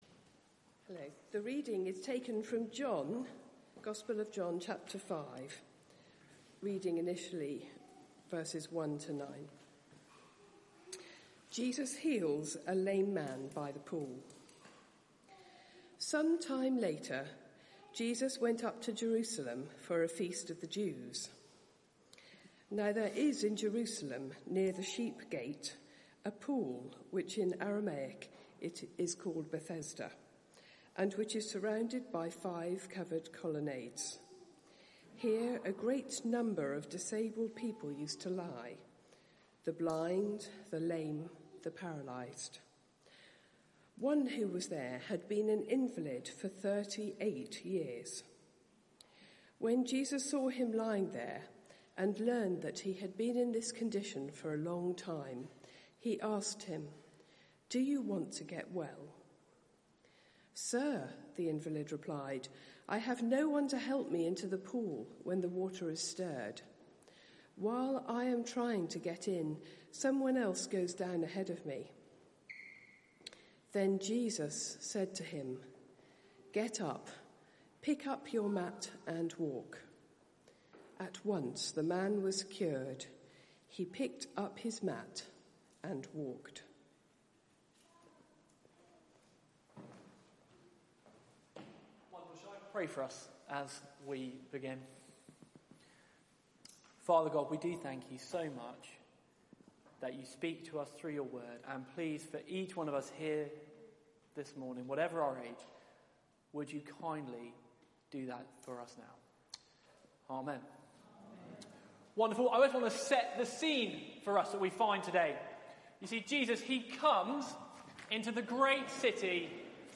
Media for 9:15am Service on Sun 16th Aug 2020 10:00 Speaker
Passage: John 5:1-15 Series: Meetings with Jesus (in John's Gospel) Theme: Sermon